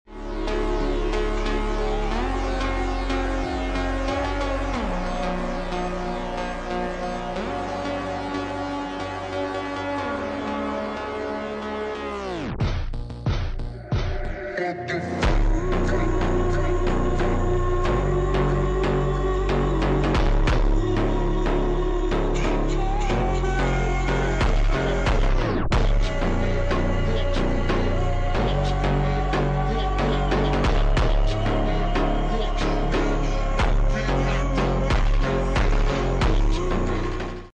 GEARBOX DRIFT GTR R34 🍁 sound effects free download